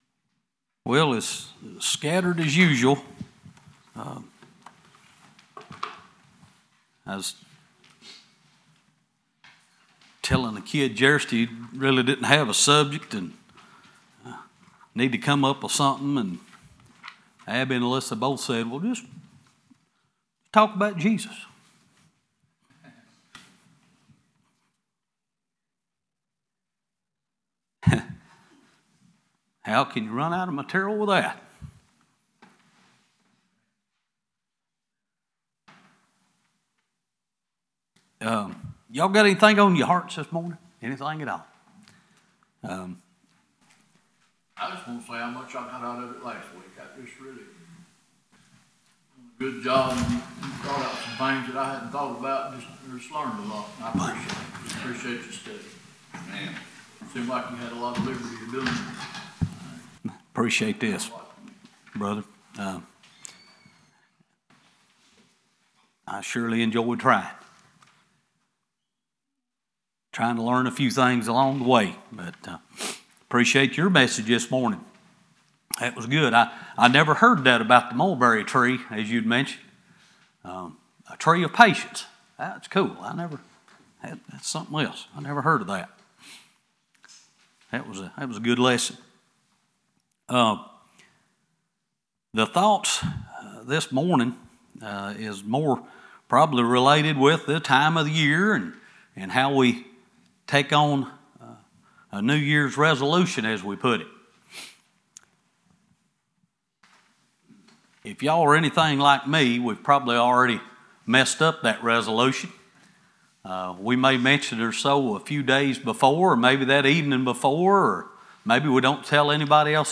January 2, 2022 New Year’s Resolutions Series: Sunday School Passage: Exodus 12:2, Deuteronomy 16:1, Isaiah 43:18-19, Ephesians 4:21-24, Psalms 37:4, Colossians 3:1-10 Service Type: Sunday School « Unto Us A Son Is Given…